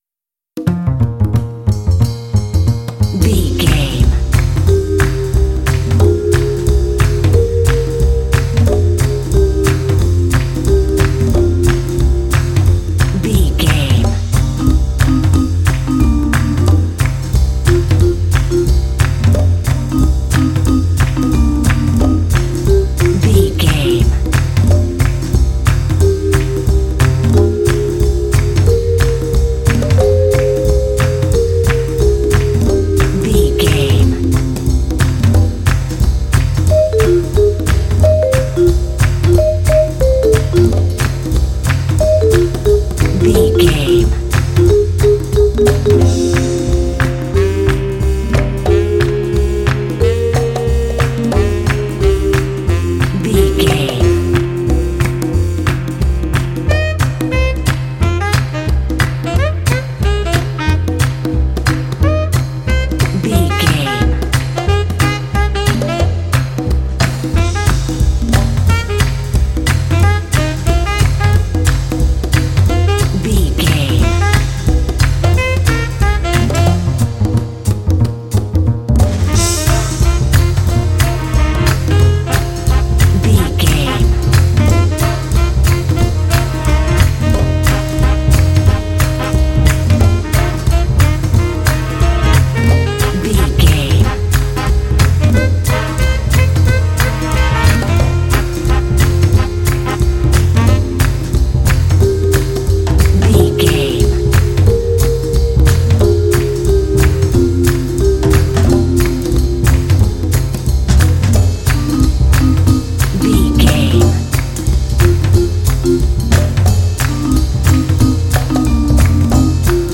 Uplifting
Aeolian/Minor
funky
bouncy
groovy
drums
brass
percussion
bass guitar
saxophone